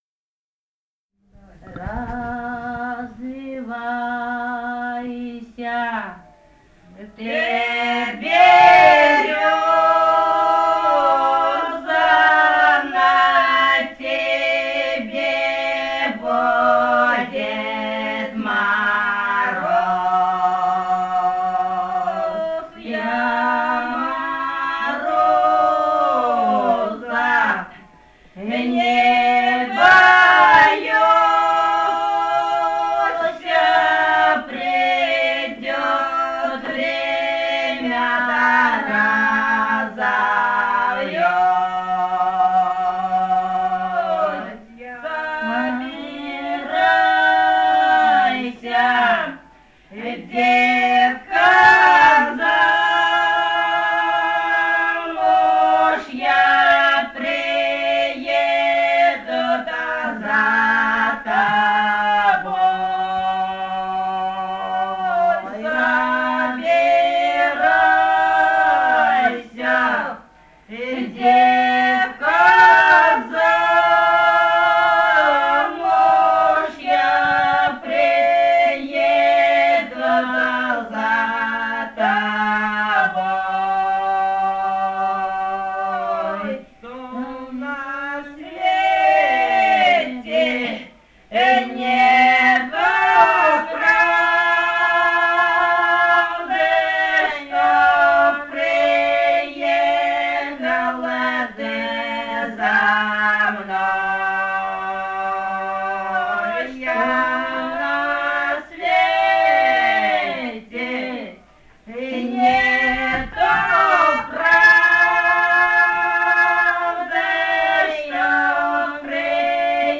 Этномузыкологические исследования и полевые материалы
«Развивайся, ты берёза» (лирическая беседная).
Бурятия, с. Петропавловка Джидинского района, 1966 г. И0903-11